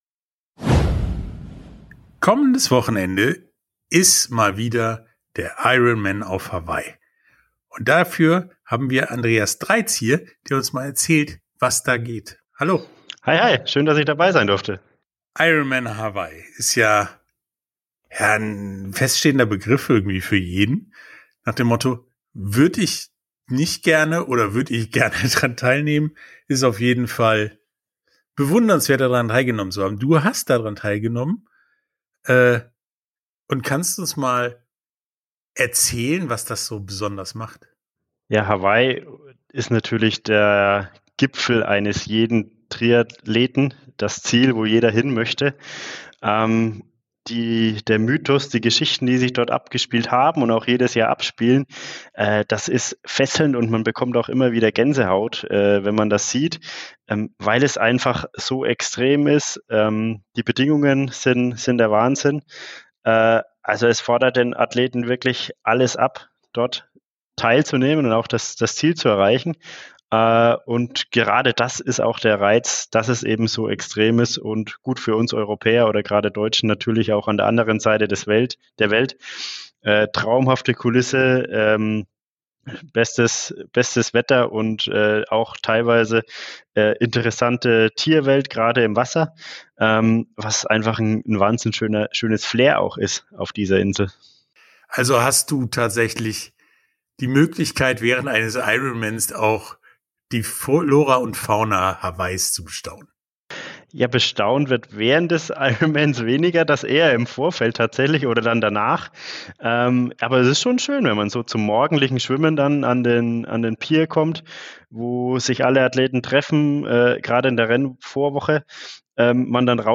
Interview komplett